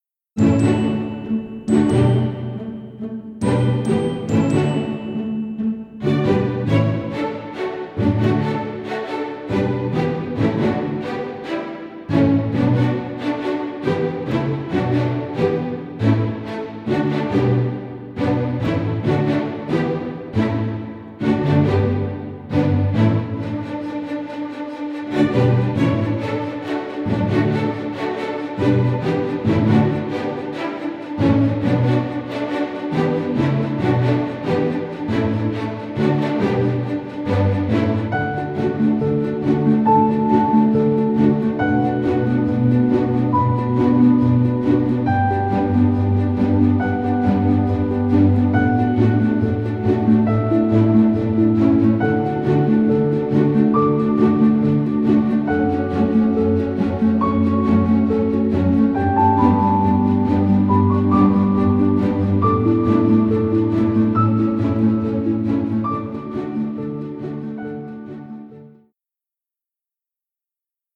sensitive and gentle score
gentle transparency and full orchestra-like ambience